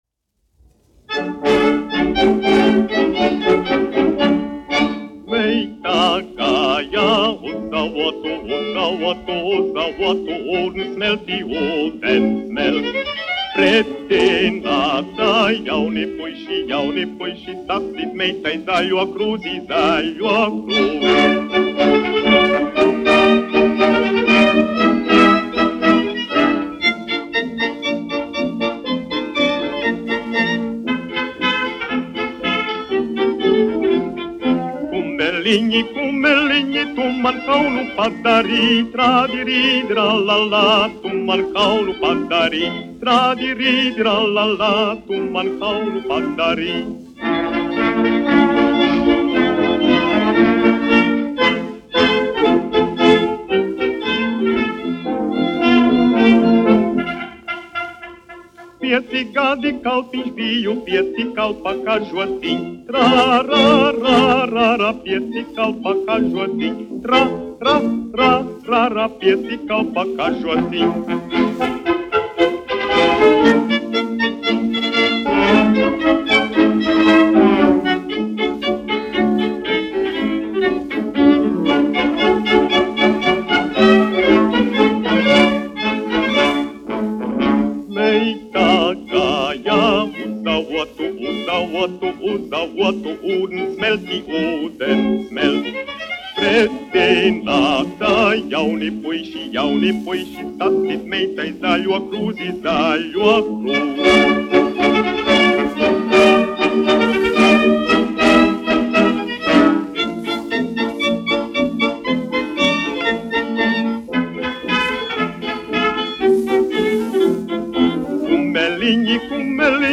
1 skpl. : analogs, 78 apgr/min, mono ; 25 cm
Fokstroti
Latviešu tautasdziesmas
Popuriji
Latvijas vēsturiskie šellaka skaņuplašu ieraksti (Kolekcija)